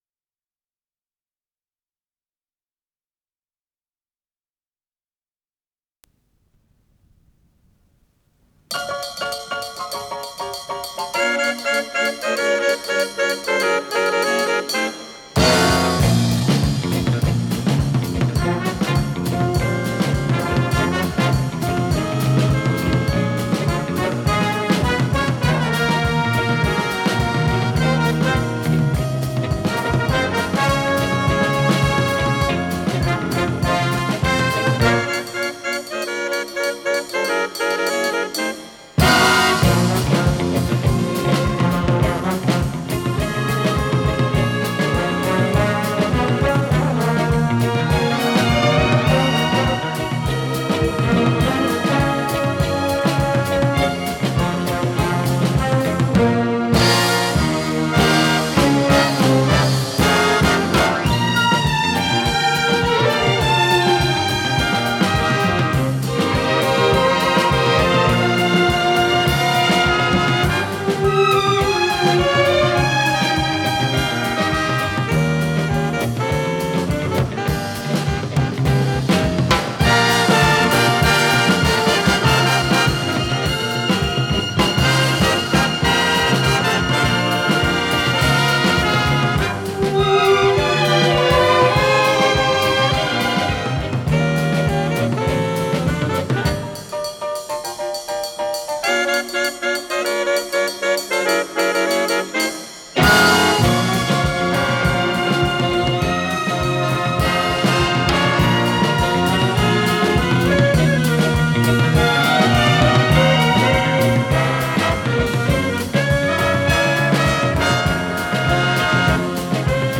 с профессиональной магнитной ленты
ПодзаголовокСи бемоль минор
ВариантДубль моно